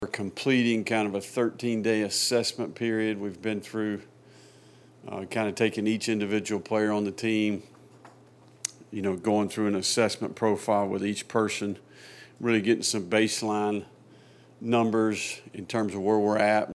In Friday’s press conference, head coach Billy Napier explained his phase one of transitioning to Florida football.